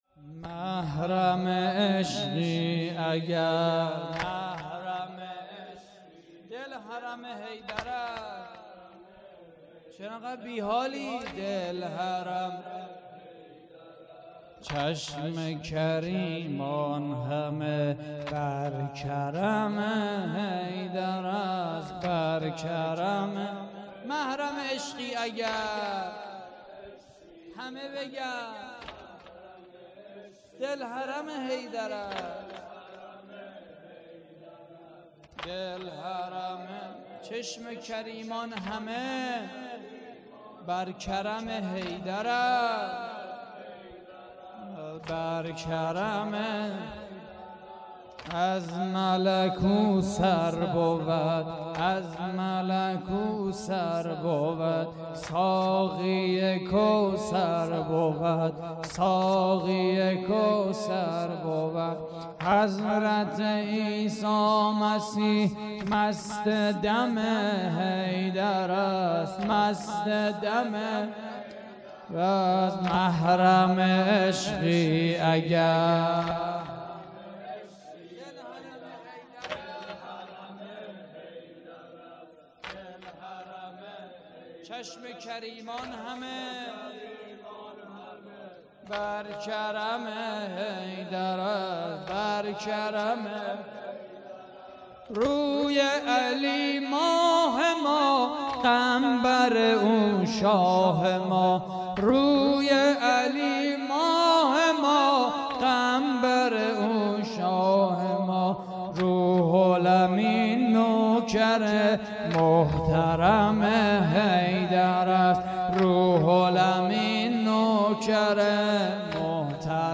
مراسم شهادت حضرت امیر (ع) (19رمضان)